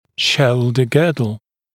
[‘ʃəuldə ‘gɜːdl][‘шоулдэ ‘гё:дл]плечевой пояс